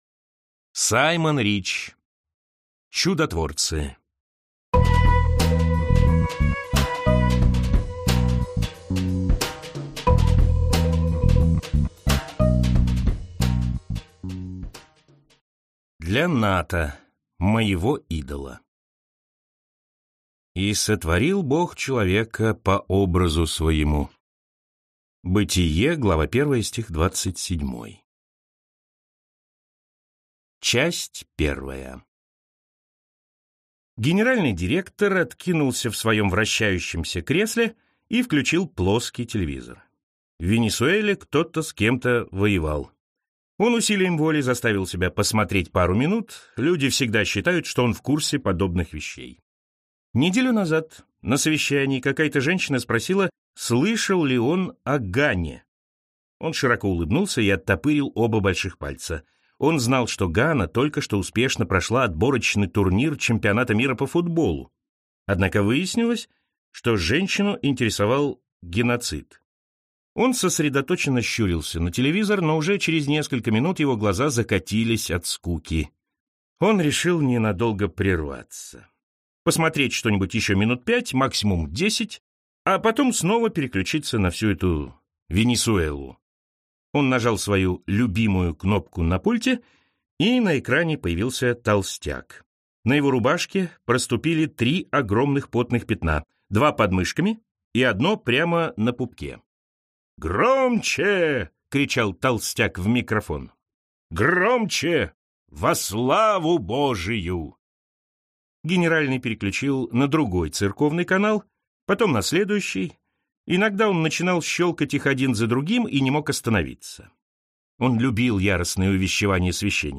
Аудиокнига Чудотворцы | Библиотека аудиокниг